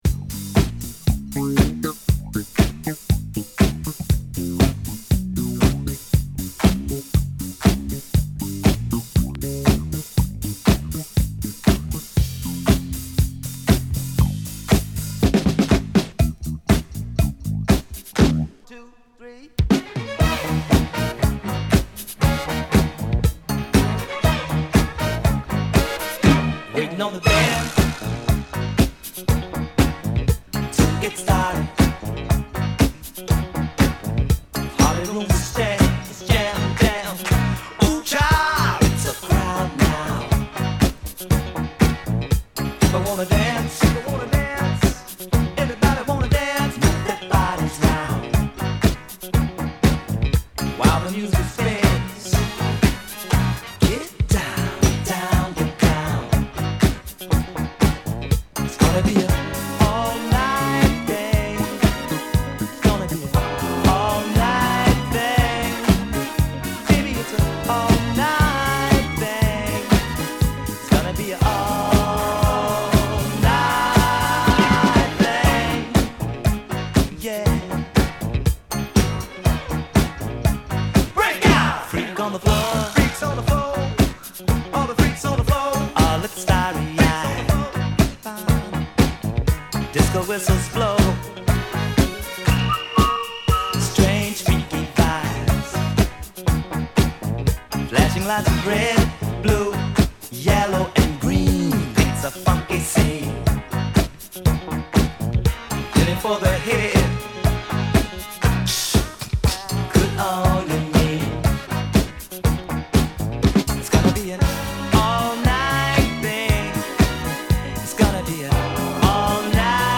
ファンクなベースが絡むミッドテンポのディスコビートにポジティブなヴォーカルが乗るモダンなファンクブギー！